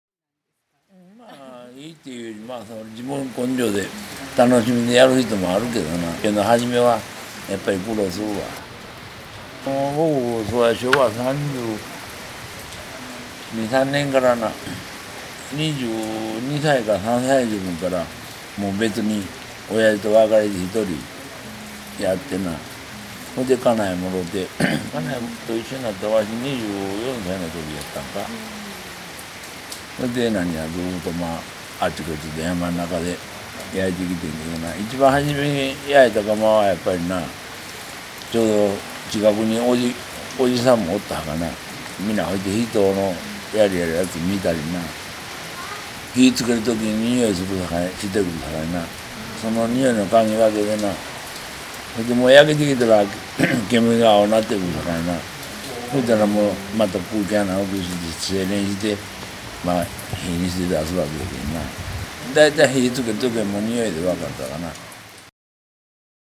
みちの途中で伺ったお話を、音声とともに伝えていきます。
紀州備長炭記念公園の裏手にまわると、大きな炭焼き窯に赤々と火が入っていました。